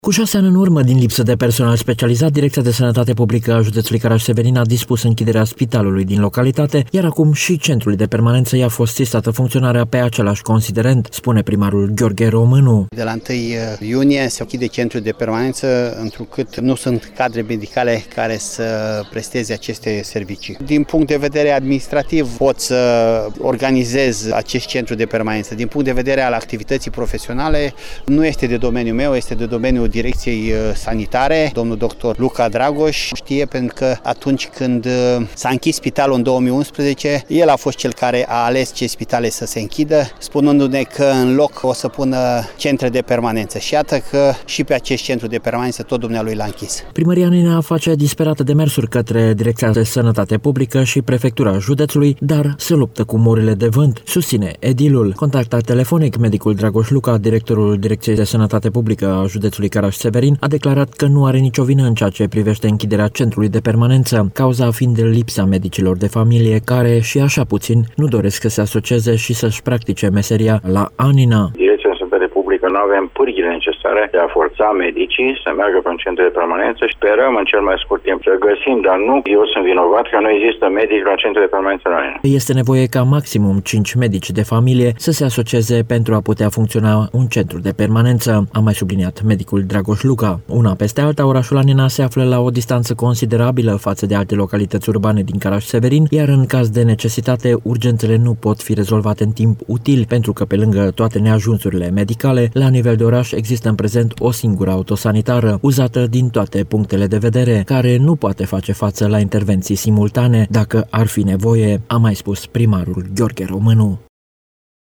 După ce cu câteva luni în urmă, din lipsă de personal specializat, Direcţia de Sănătate Publică (DSP) a judeţului Caraş-Severin a dispus închiderea spitalului din localitate, acum şi centrului de permanenţă i-a fost sistată funcționarea pe același considerent, a declarat pentru Radio Reşiţa primarul Gheorghe Românu.
Contactat telefonic, medicul Dragoş Luca – directorul DSP Caraş-Severin a declarat că, nu are nicio vină în ceea ce priveşte închiderea centrului de permanenţă, cauza fiind lipsa medicilor de familie care nu doresc să se asocieze şi să-şi practice meseria la Anina.